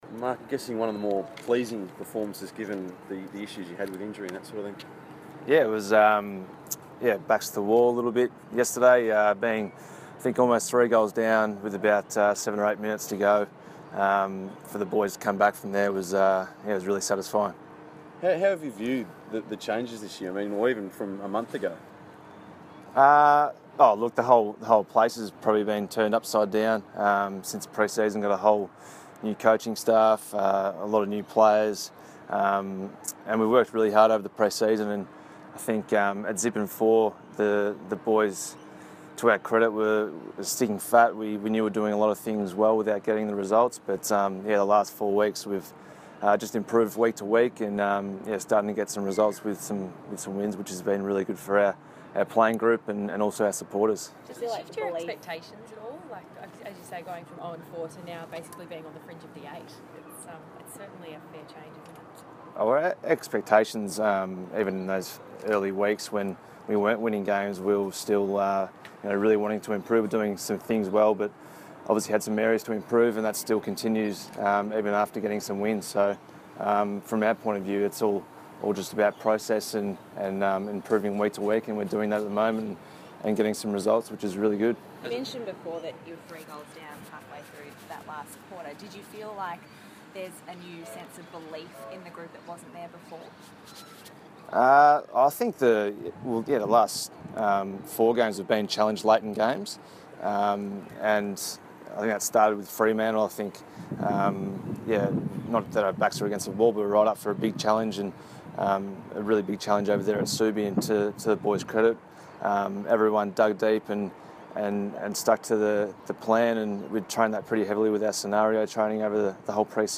Marc Murphy press conference - May 16
Captain Marc Murphy spoke to the media at Ikon Park on Monday morning after Carlton's Round 8 win over the Power.